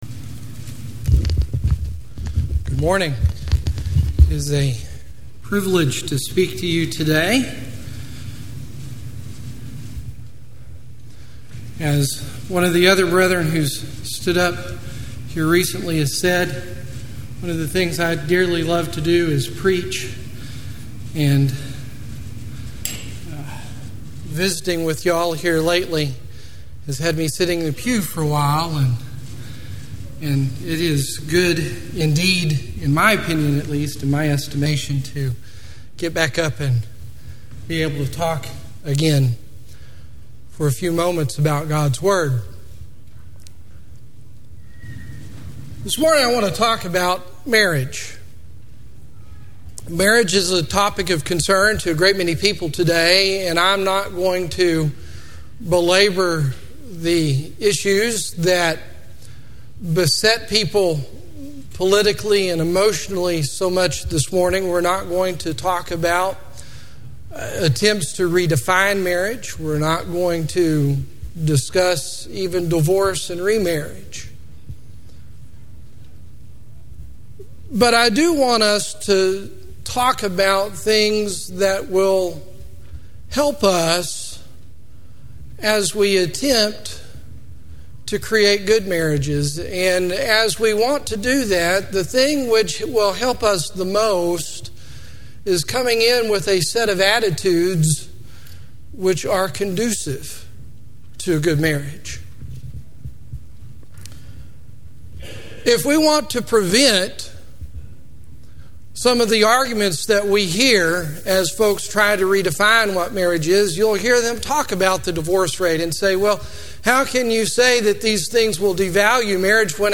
Service Type: Guest Speaking